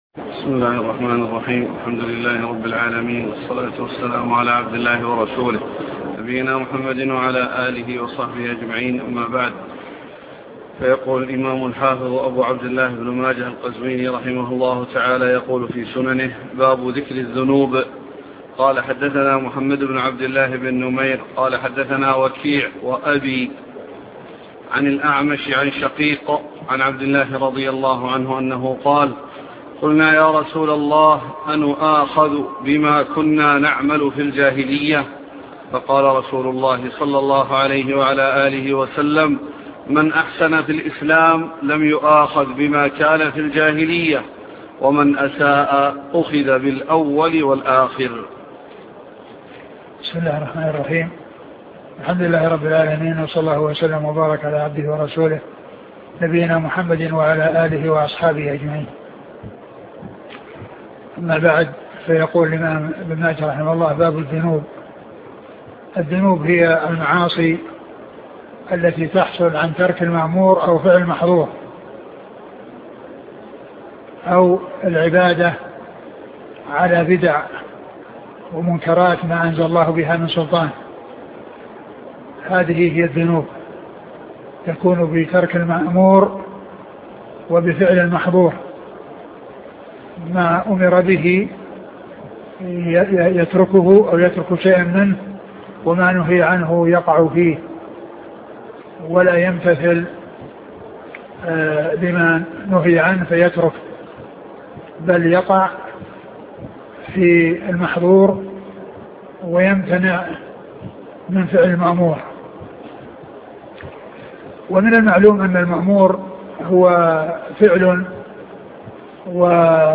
شرح سنن ابن ماجه الدرس عدد 309